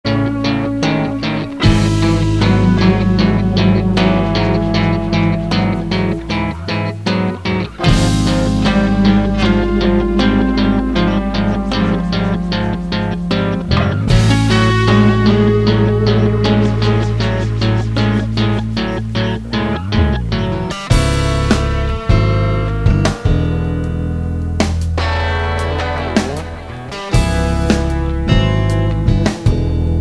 chitarre
tastiere
basso elettrico
batteria
percussioni